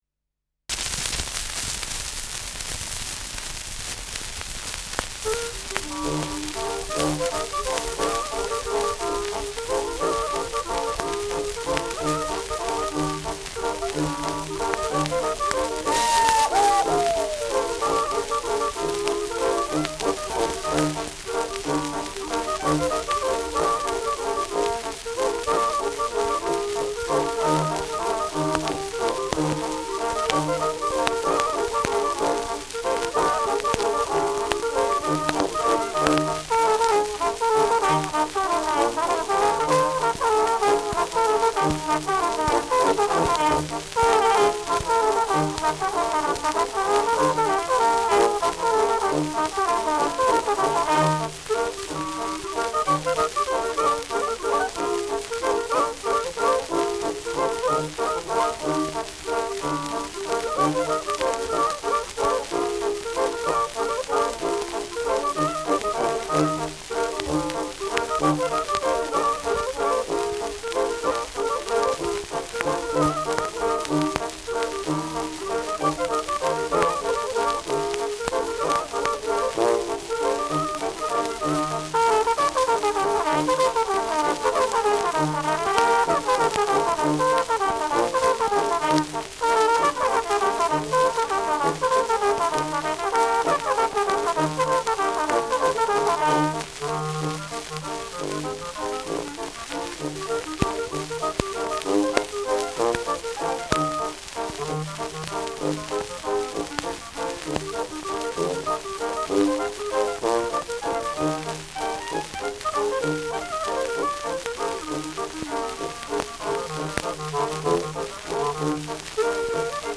Schellackplatte
präsentes Rauschen
Mit Juchzern.